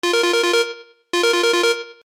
/ G｜音を出すもの / G-01 機器_警告音_アラーム_電話着信
アラート 警告音 C-Low-Speed150-08